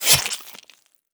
combat / weapons / default_swingable / flesh3.wav
flesh3.wav